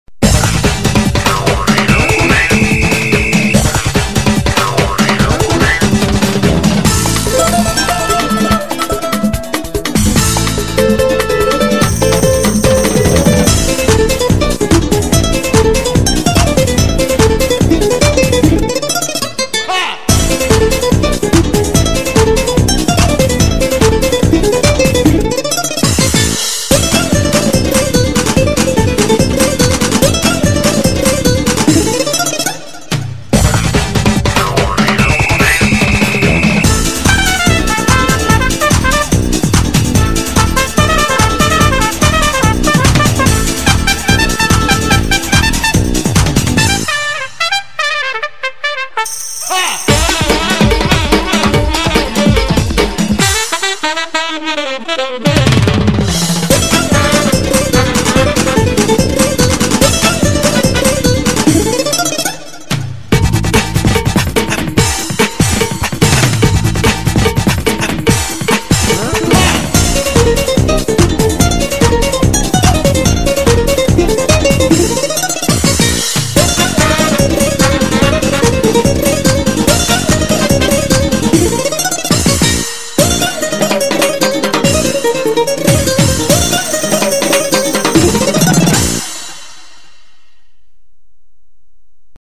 역시나 멕시코의 수출을 위해 그나라 느낌이 물씬 나게끔 쓰여진 컨셉의 연주곡입니다.